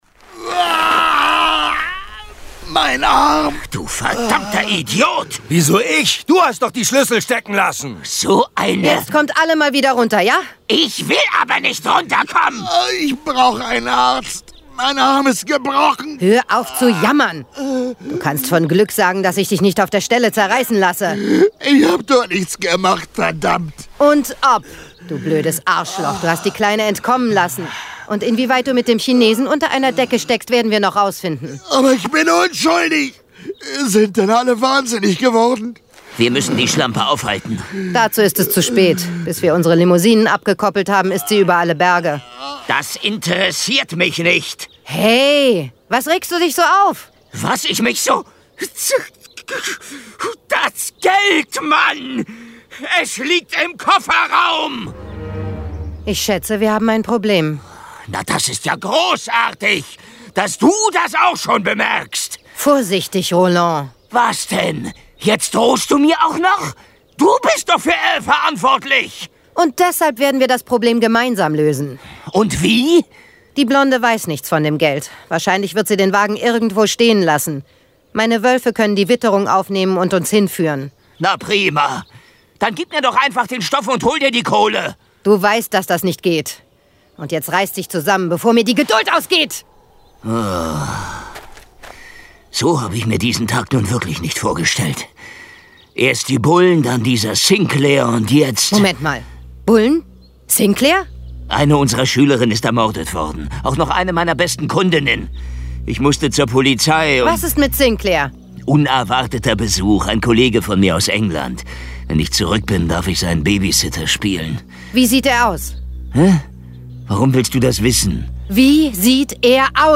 John Sinclair - Folge 48 Lupinas Todfeind - Teil 2 von 2. Hörspiel.